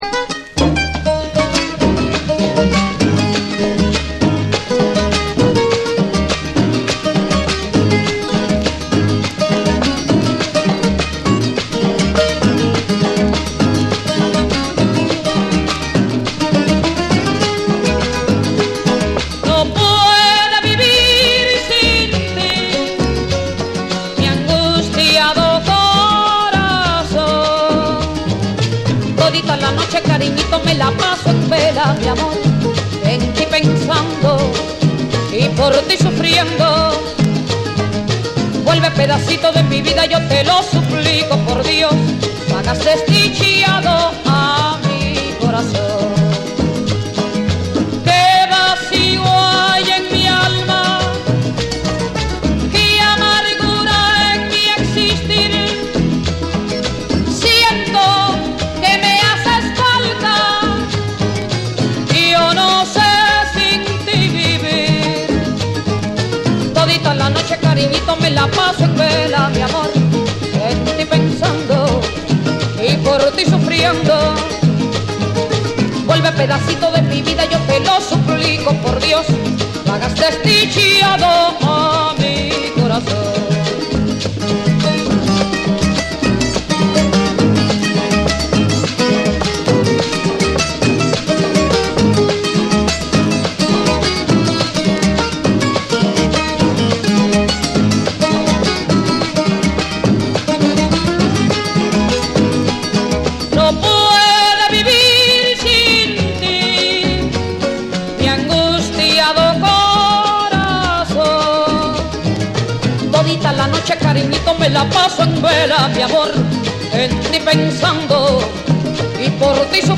キューバ出身の夫婦デュオ
伝統的なソンやグアラーチャ、グアヒーラを中心に、温かみのあるギターとリズミカルなパーカッション
WORLD